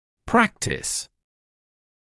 [‘præktɪs][‘прэктис]практика; клиника (чаще частная); практиковать